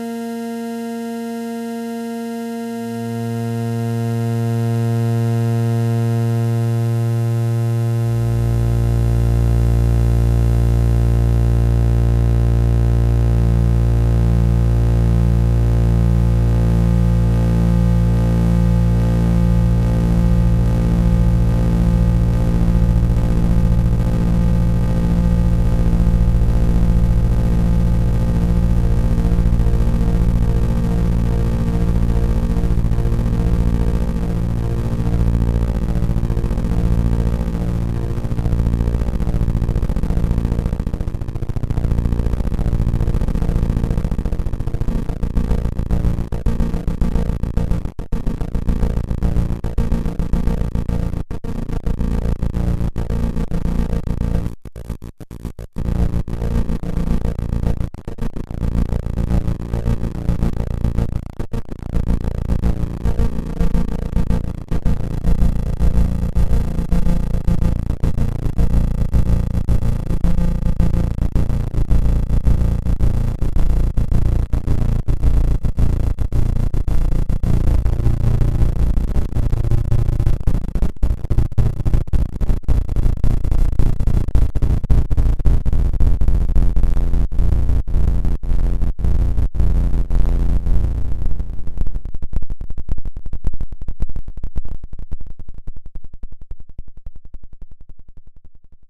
suboscillator.mp3